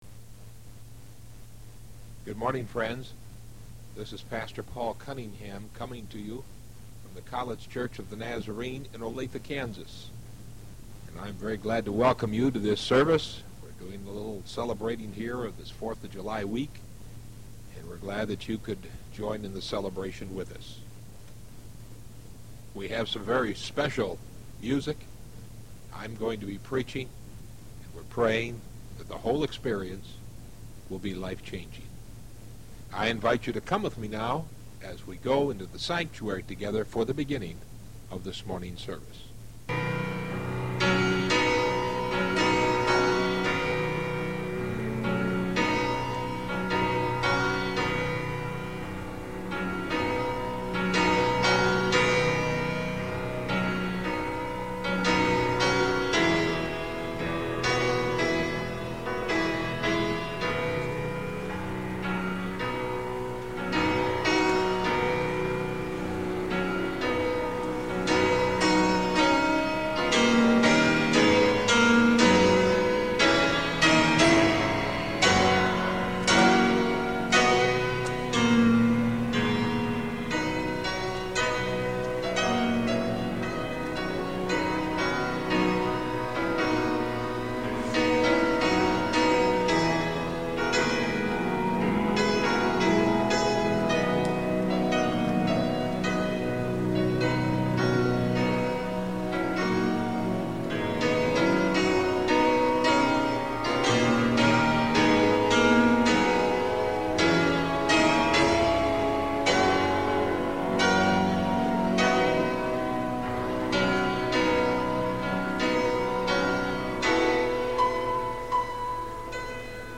Audio Sermon: Choosing To Be Free Acts 9:1-22